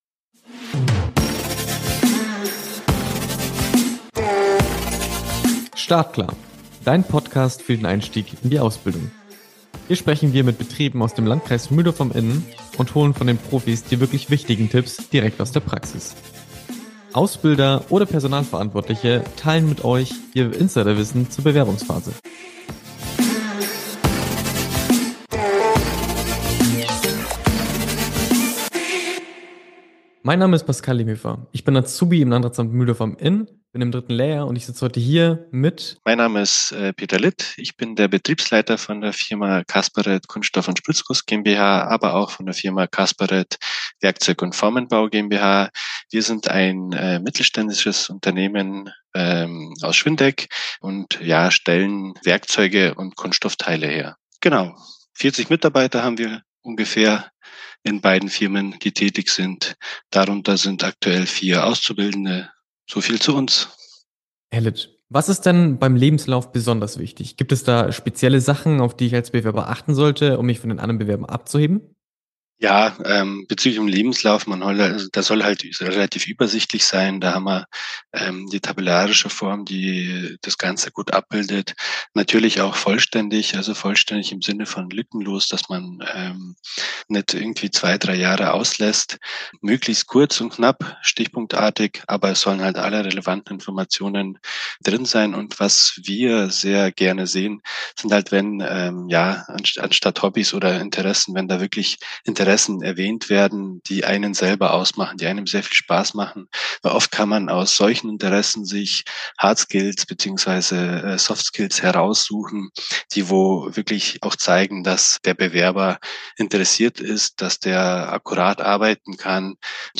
im Gespräch mit der Firma Kaspareth